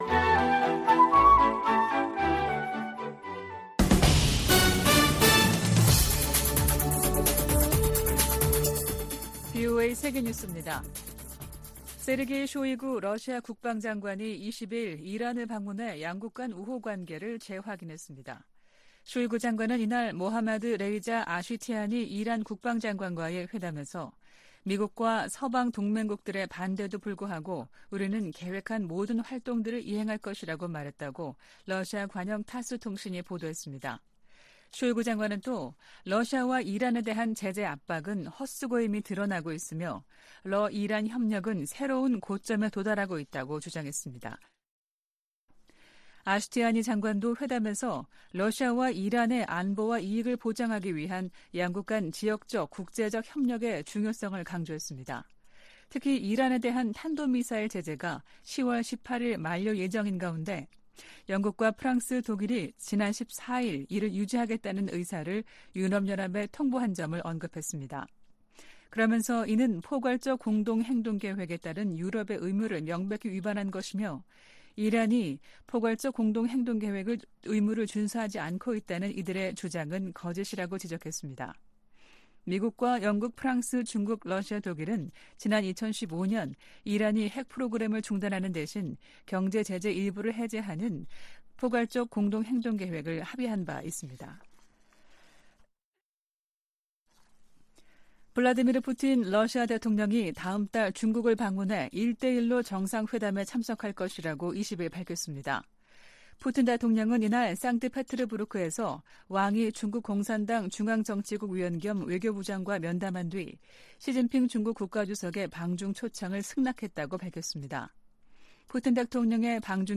VOA 한국어 아침 뉴스 프로그램 '워싱턴 뉴스 광장' 2023년 9월 21일 방송입니다. 조 바이든 미국 대통령이 유엔총회 연설에서 북한 정권의 거듭된 유엔 안보리 결의 위반을 규탄했습니다. 한국 정부가 러시아 대사를 초치해 북한과의 군사 협력 움직임에 대해 강력히 경고했습니다. 러시아가 북한 같은 나라에 의존할 수밖에 없을 정도로 고립됐다고 로이드 오스틴 미 국방장관이 지적했습니다.